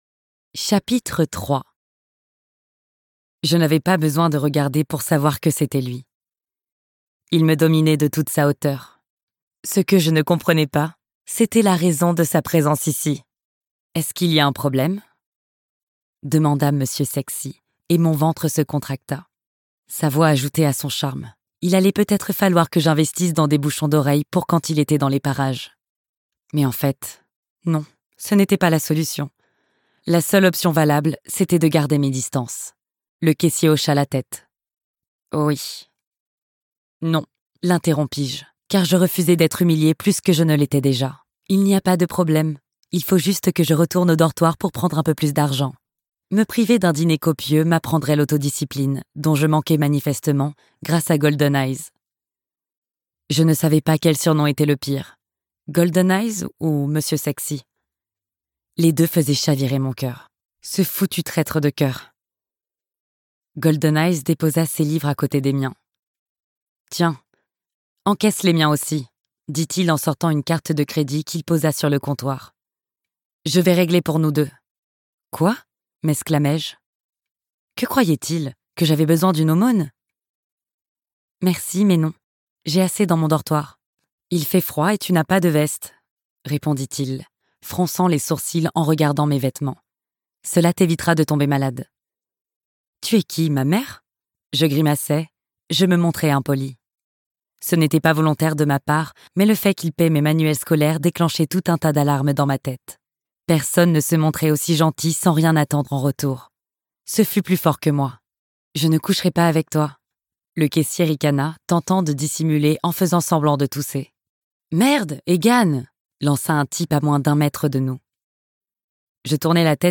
Le Compagnon Dragon - Livre audio de fantasy